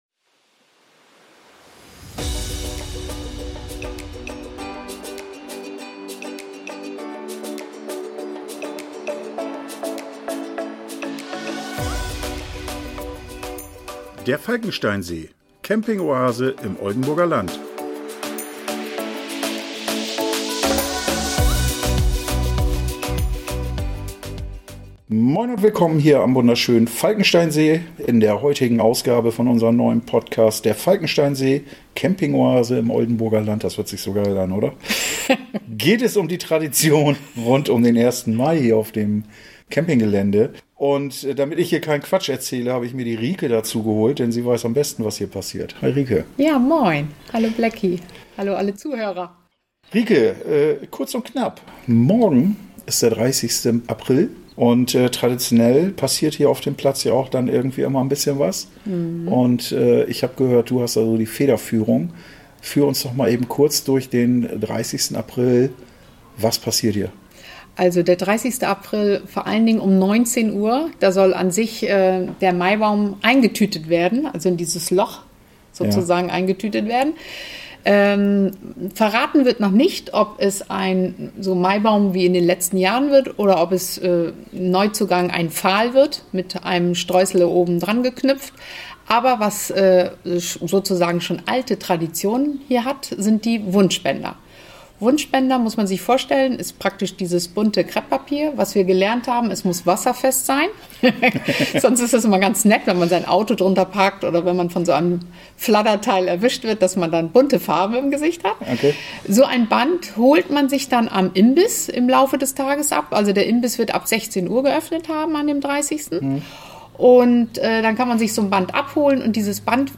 Wer hätte gedacht, dass ich bereits in der 2. Folge über ein Wort "piepen" muss... Hört gerne rein, was am Falkensteinsee traditionell geschieht, wenn in den Mai getanzt wird.